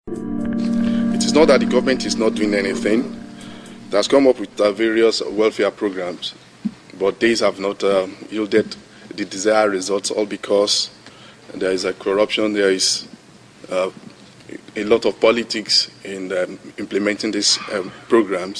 To partially respond to one of this blog posting’s comments, I am adding a short audio clip from one of the representatives at a UN meeting who basically stated that welfare programs implemented to fight poverty are not very effective due to corruption and “politics.”